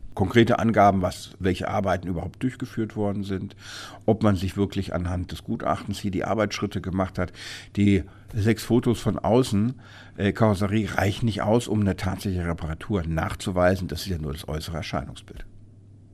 O-Ton: Nach fiktiver Abrechnung kein Anspruch auf Löschung in der Versicherer-Datenbank – Vorabs Medienproduktion